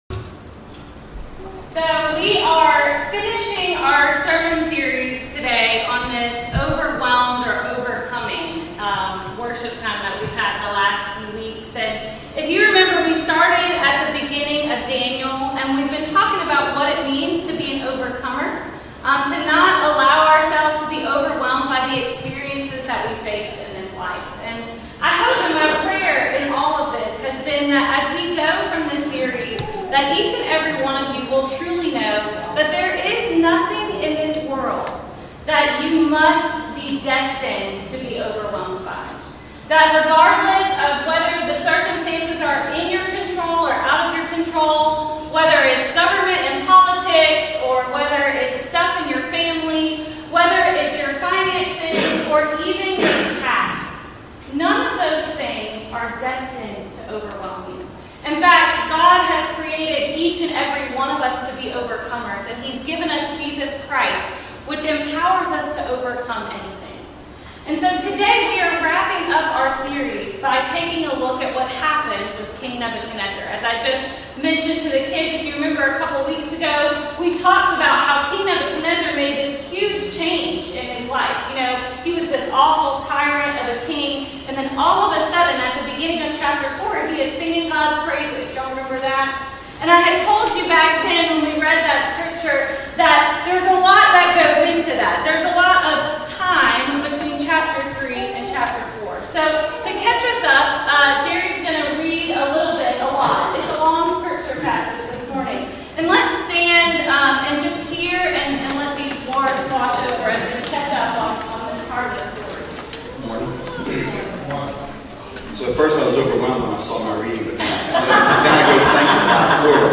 St. Charles United Methodist Church Sermons